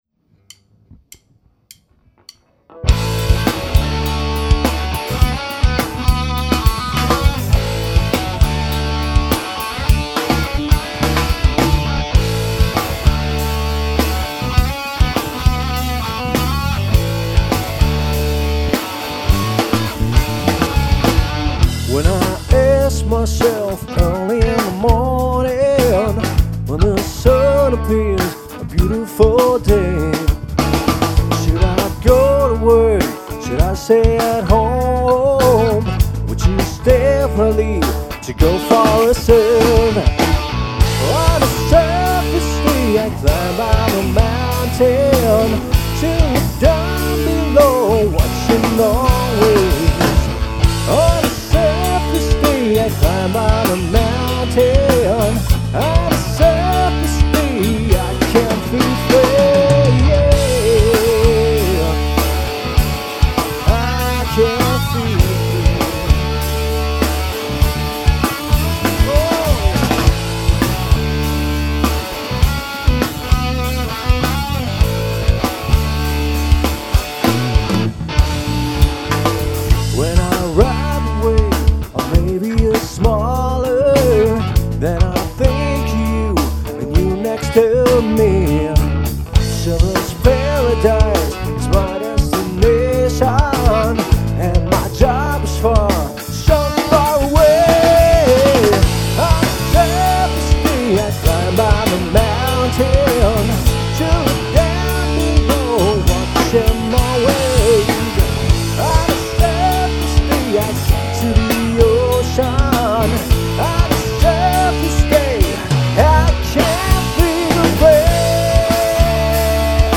(Live-Mitschnitt – unbearbeitet)
fettes Gitarrenlick zu Beginn dieses Piece of Rock